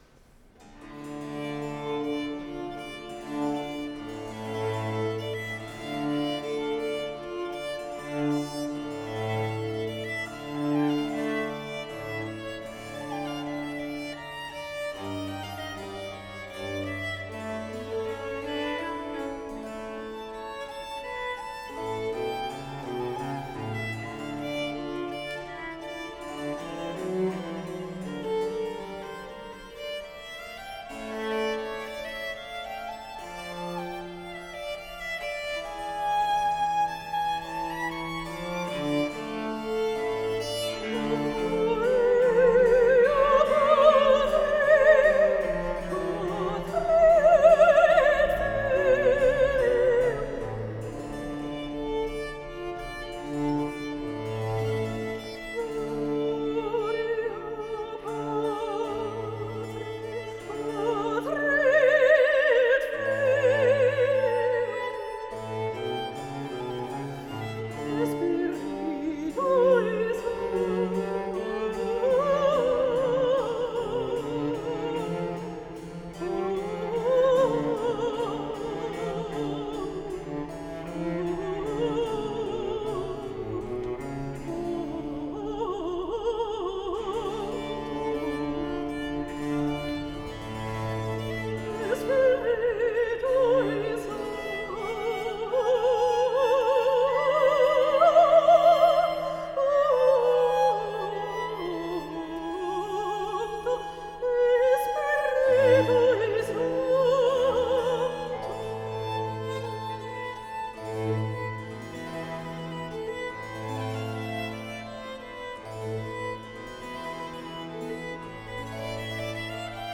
10/04/2012 11:12 Archiviato in: Live recording
per violino principale, 2 flauti, archi e Continuo
per voce, orchestra e Continuo
Chiesa dell’Annunziata, Torino